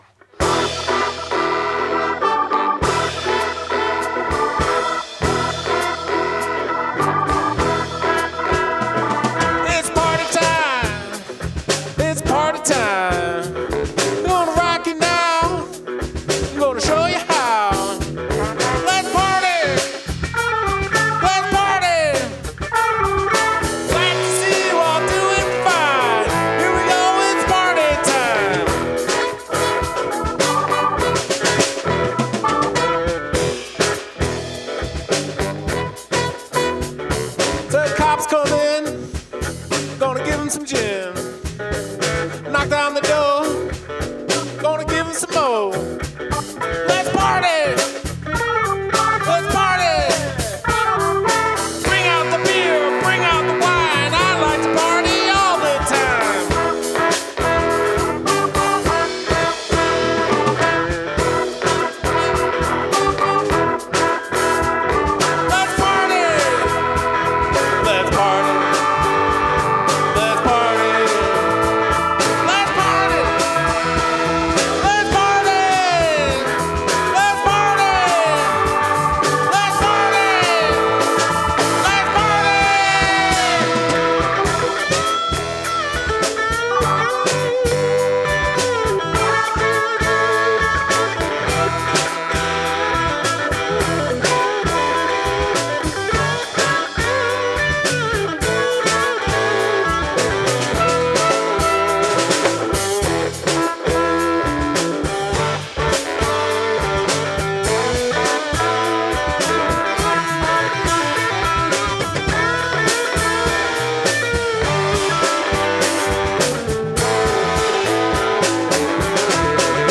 Recording Sessions